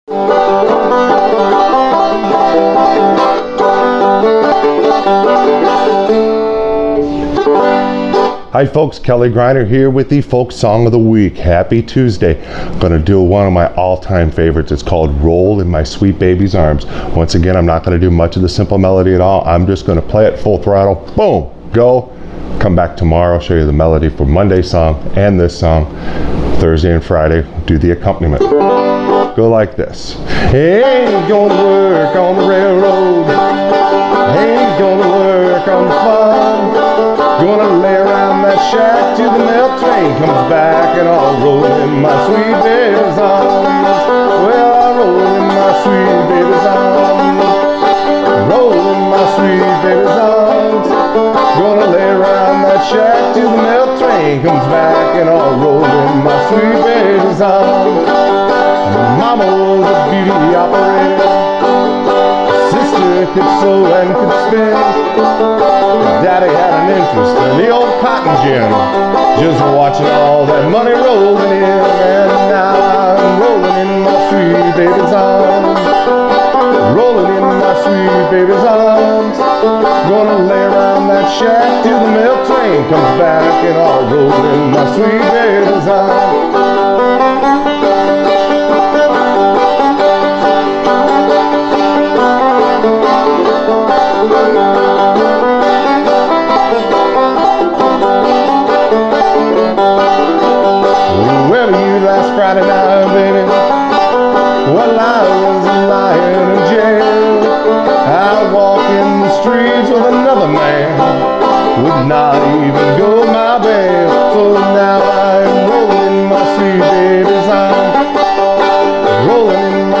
Don’t be afraid to just strum and sing, that is the best way to find the melody notes for ANY song.